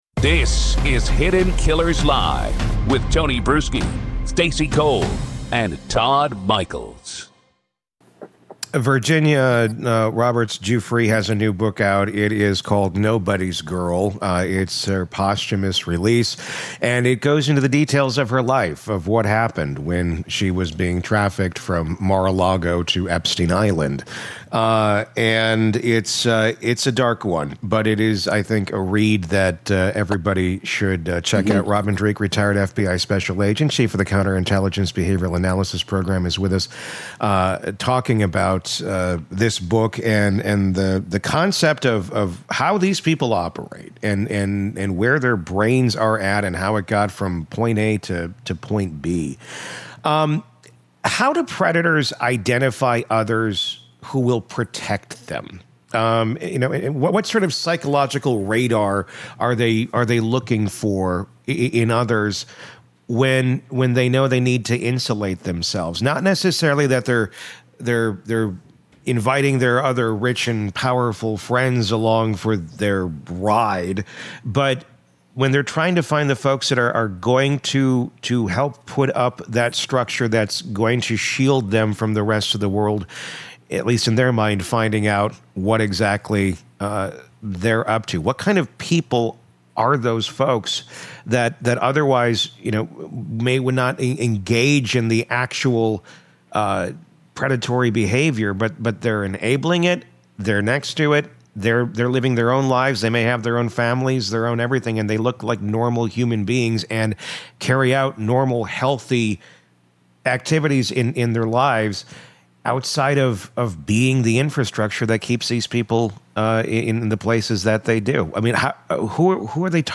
In this extended, unsparing interview